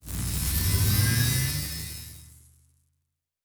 Weapon 08 Load (Laser).wav